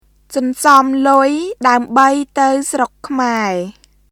[ソンソム・ルイ・ダウムバイ・タウ・スロック・クマエ　sɔnsɔm lʊi daəmbəi tə̀w srok kʰmae]